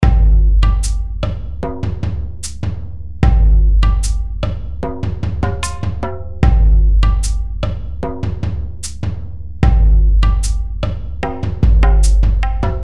描述：牢不可破的合作项目中的一个鼓环
Tag: 75 bpm Fusion Loops Drum Loops 2.15 MB wav Key : Unknown